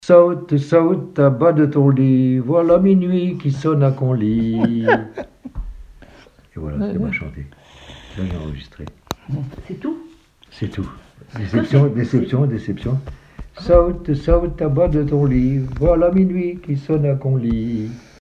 L'enfance - Enfantines - rondes et jeux
Témoignages et chansons
Pièce musicale inédite